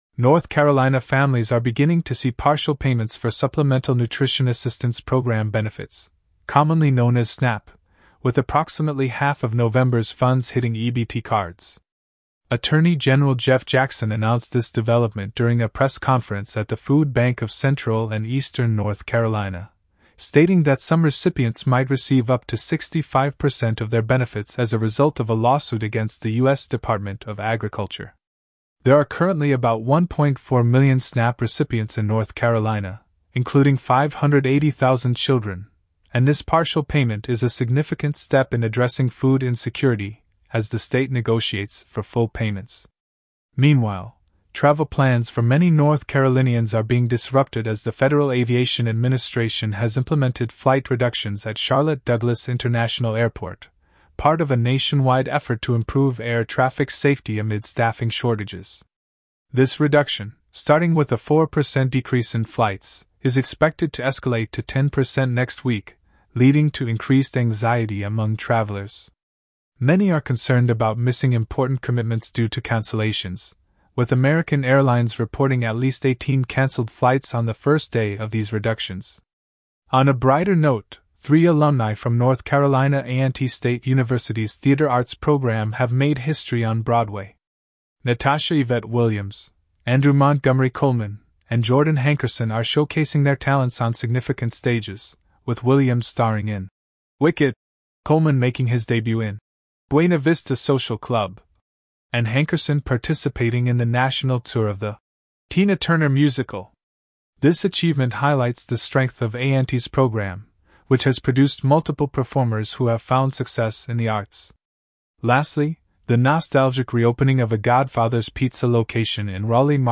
North Carolina News Summary